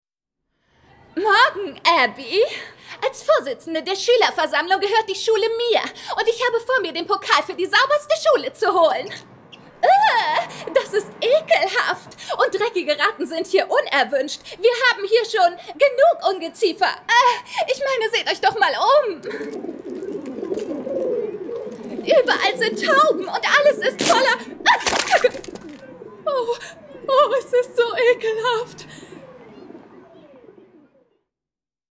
Sprecherin und Schauspielerin. Synchronsprecherin, Studiosprecherin
Sprechprobe: Industrie (Muttersprache):
female german voice over talent